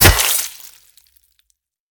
generalswing.wav